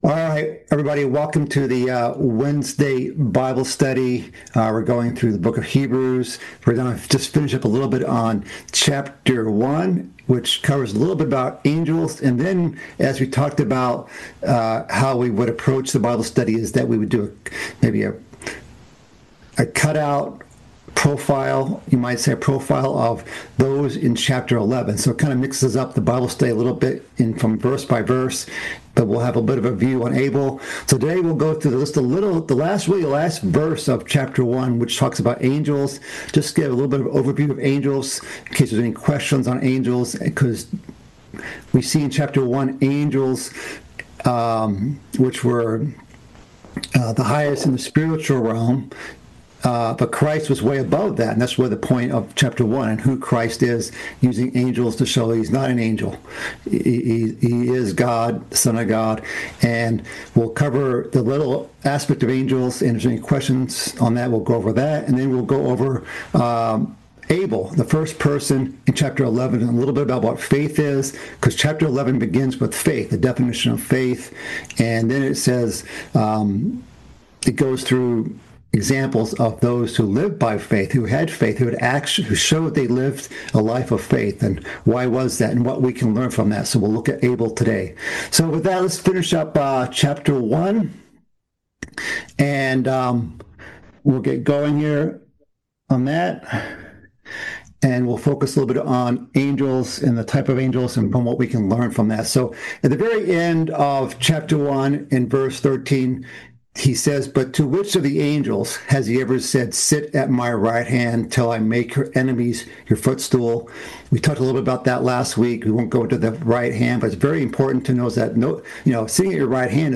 The fifth part in a series of mid-week Bible studies, covering the book of Hebrews. This session completes the first chapter of Hebrews, then delves into the first character study of the faith chapter, Abel.